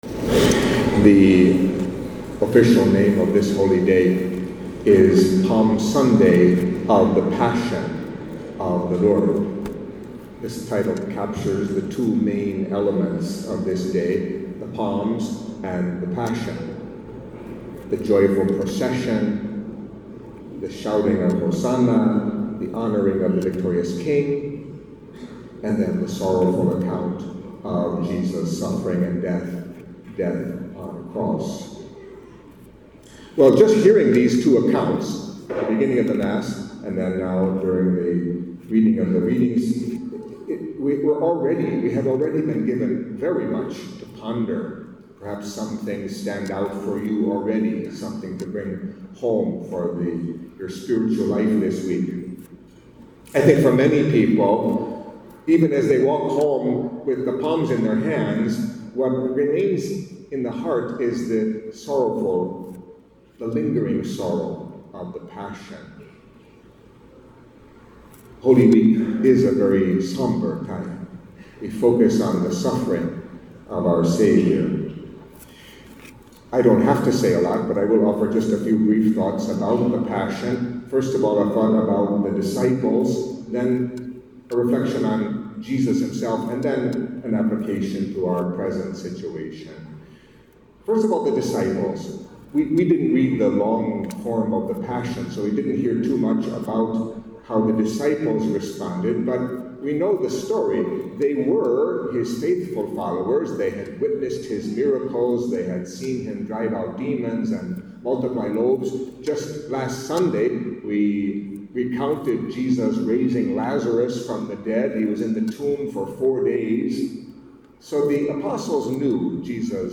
Catholic Mass homily for Palm Sunday of the Passion of the Lord